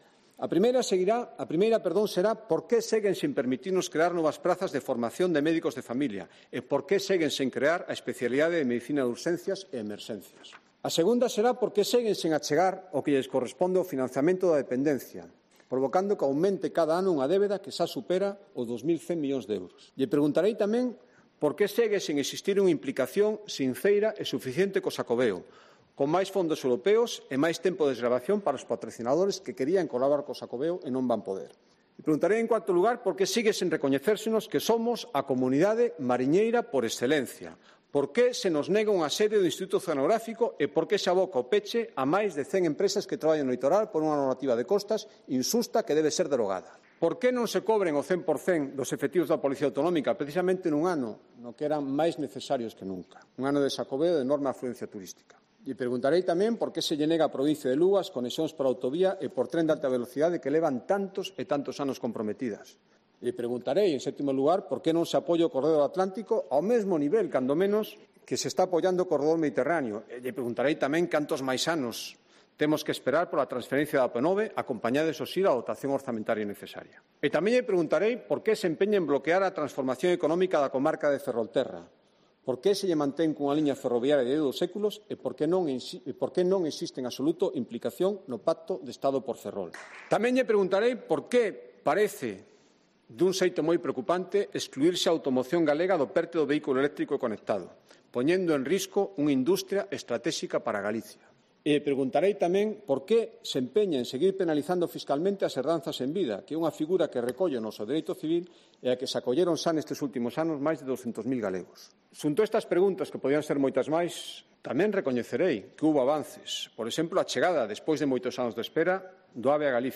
Discurso de Alfonso Rueda con deberes para el Gobierno de Pedro Sánchez